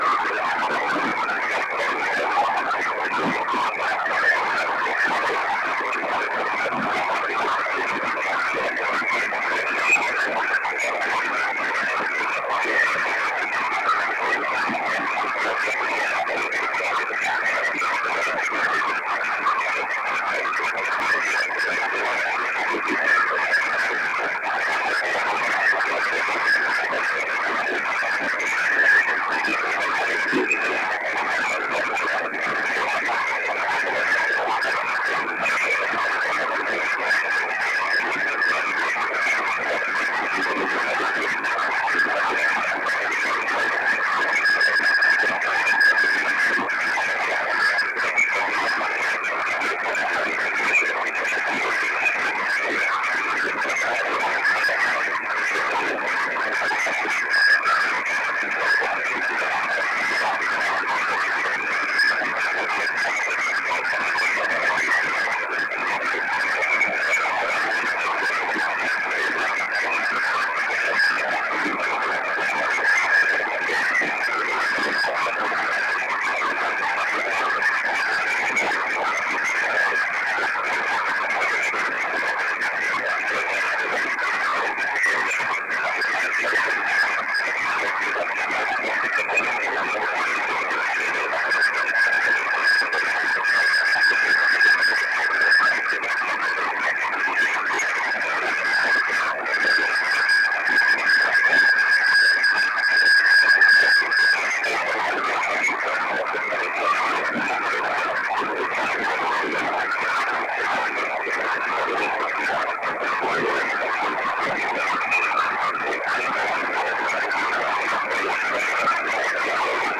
This is the frequency he is talking about 450 Mhz. What! nothing here just garble